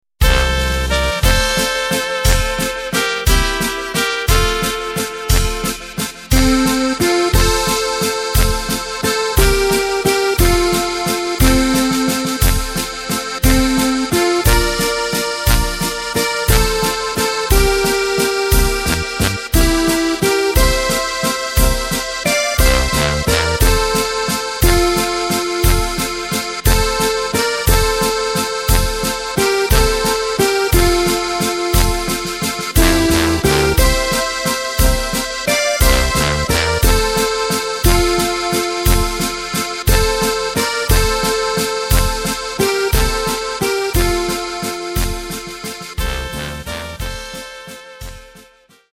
Takt:          3/4
Tempo:         177.00
Tonart:            F